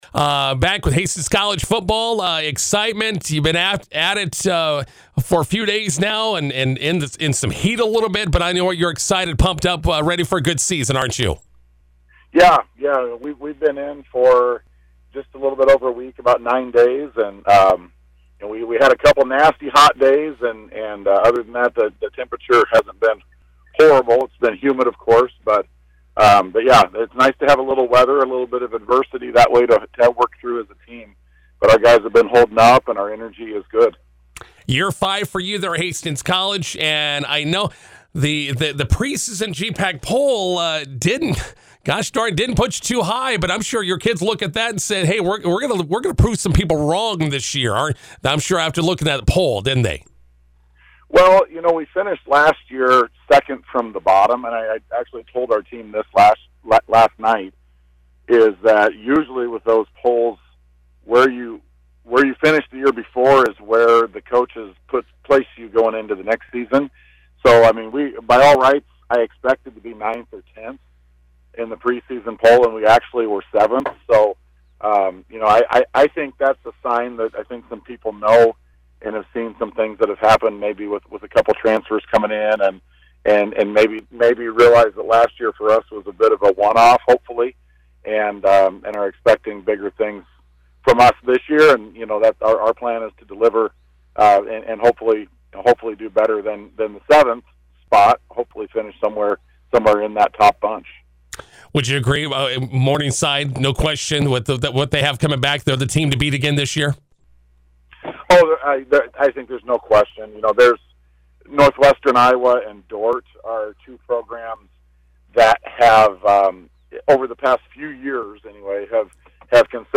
INTERVIEW: Hastings College Football prepares for season opener vs Doane.